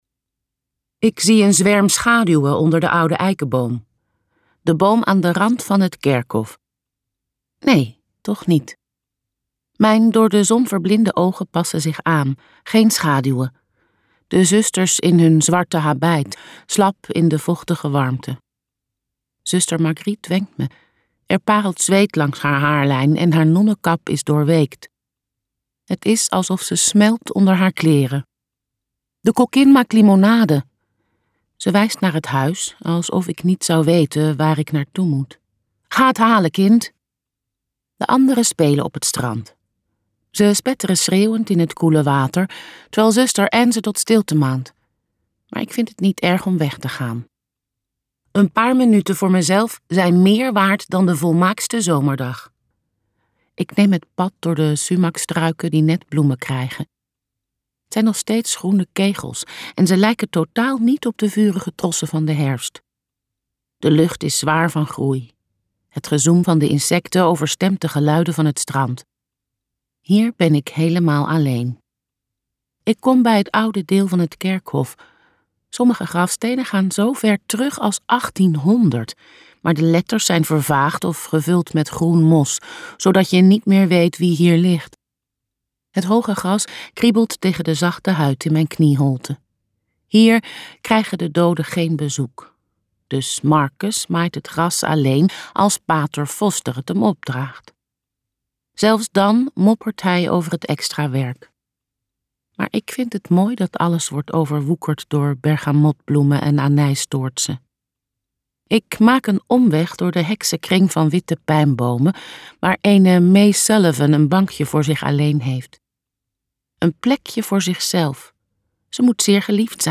Het weeshuis luisterboek | Ambo|Anthos Uitgevers